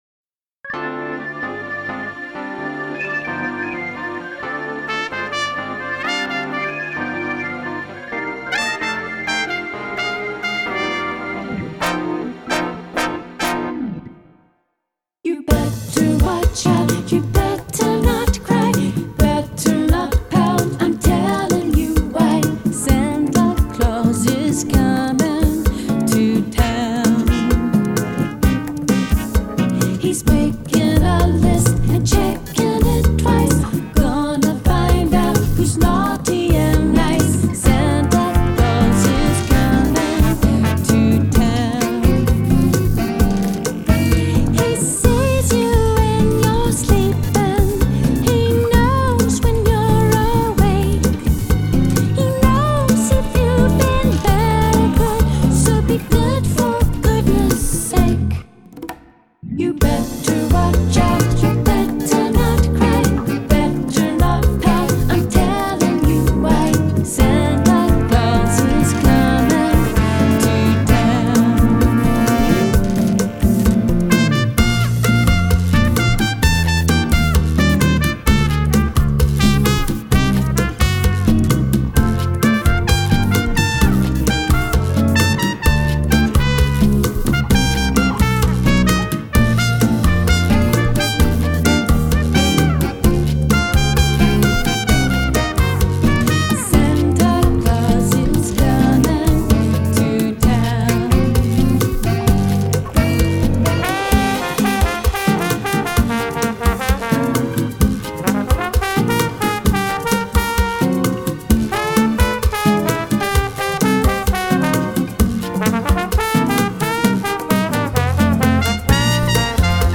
Genre: Bossa Nova Christmas